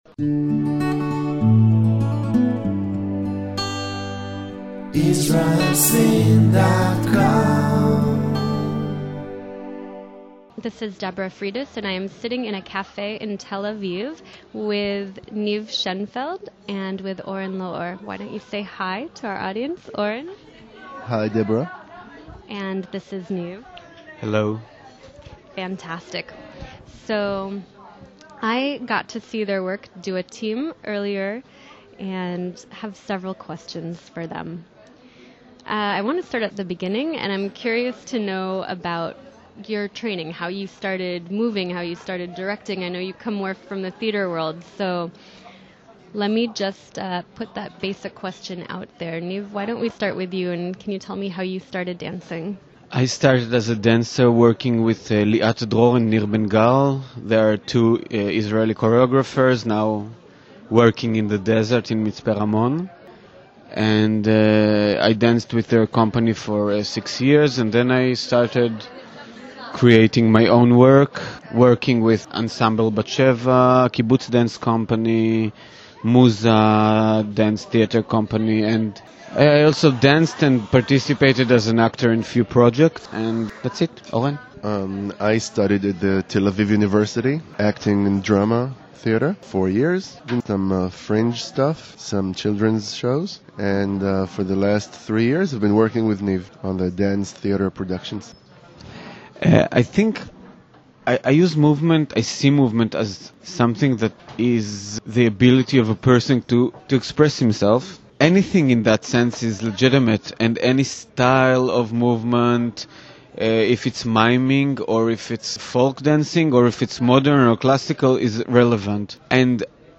Dramatic Dance Makers